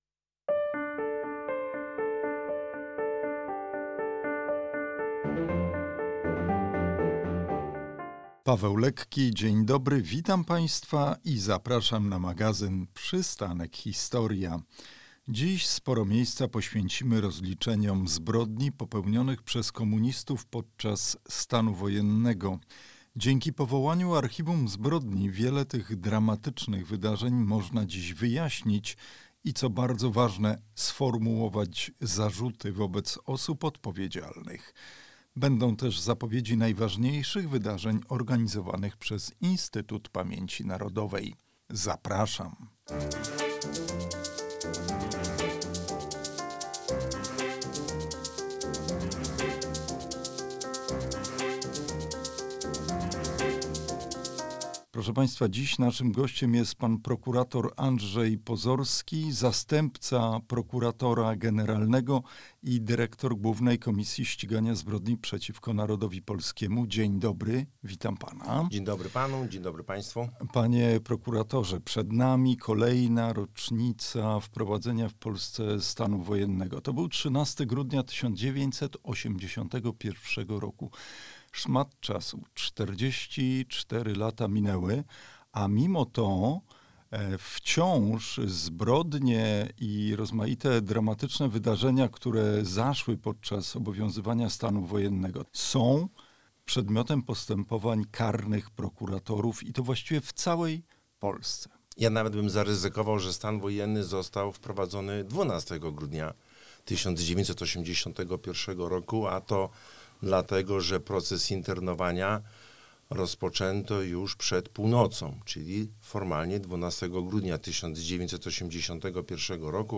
O rozliczeniach stanu wojennego mówi zastępca prokuratora generalnego i dyrektor Głównej Komisji Ścigania Zbrodni przeciwko Narodowi Polskiemu, prokurator Andrzej Pozorski.